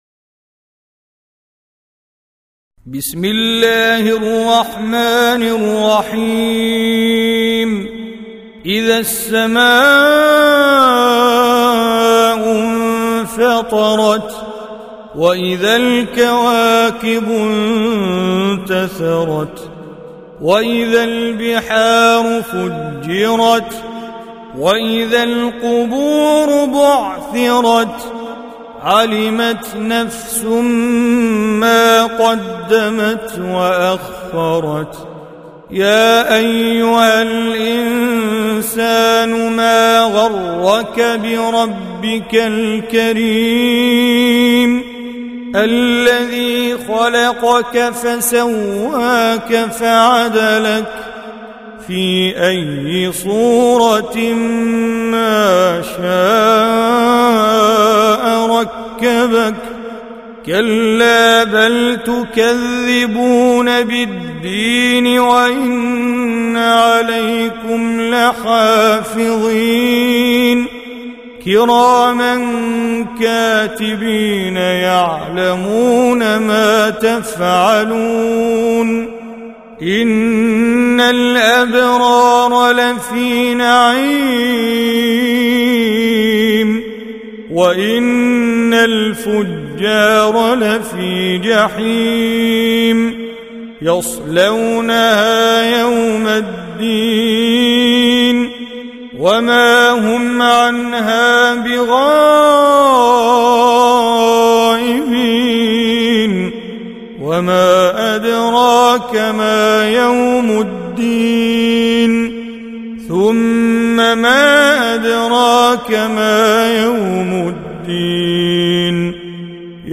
Surah Sequence تتابع السورة Download Surah حمّل السورة Reciting Mujawwadah Audio for 82. Surah Al-Infit�r سورة الإنفطار N.B *Surah Includes Al-Basmalah Reciters Sequents تتابع التلاوات Reciters Repeats تكرار التلاوات